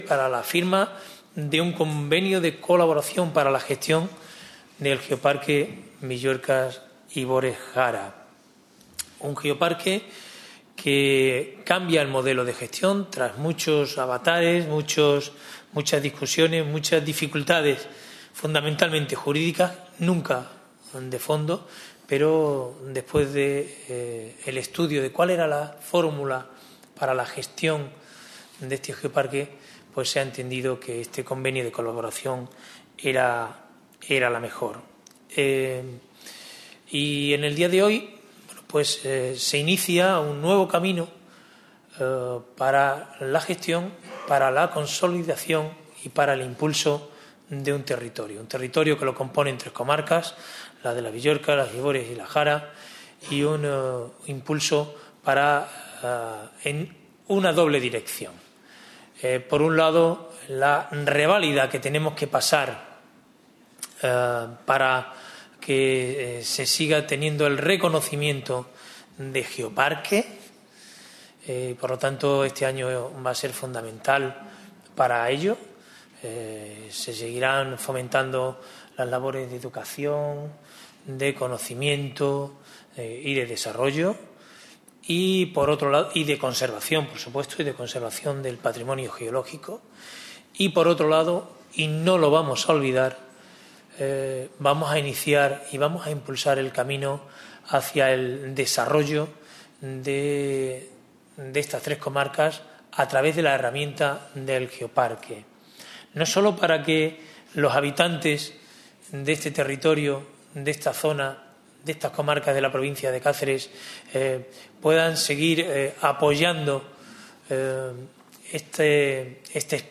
CORTES DE VOZ
FIRMA_CONVENIO_GEOPARQUE_VILLUERCAS-IBORES-JARA.mp3